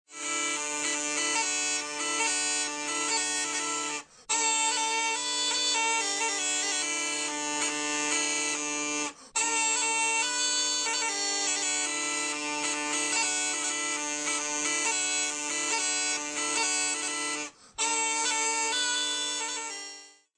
• l'aulos (nell'immagine e nell'audio), a fiato.
aulos.mp3